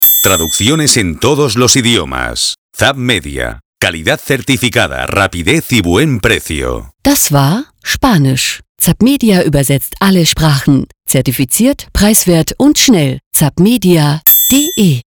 Wie zappmedia im Radio klingt, hören Sie hier (zugegeben: Eine gewisse Ähnlichkeit zur „Sendung mit der Maus“ ist nicht unbeabsichtigt…):